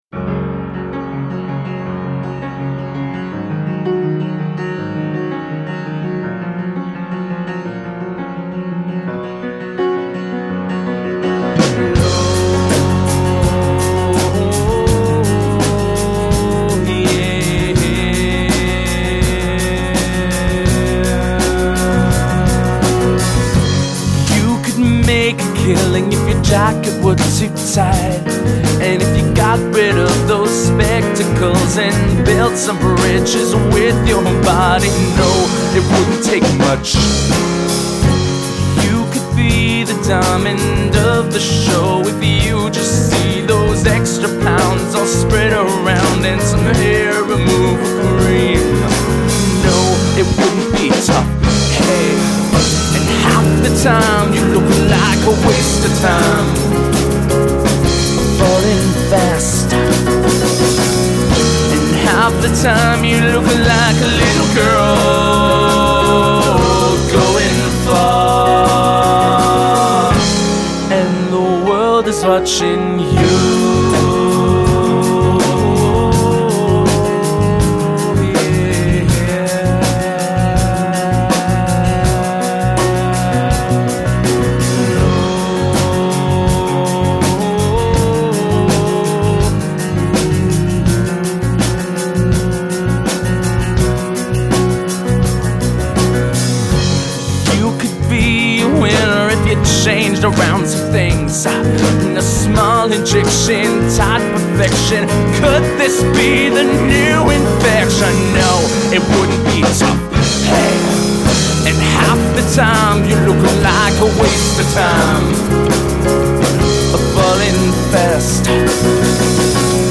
the catchy title track that seems to be everyone's favorite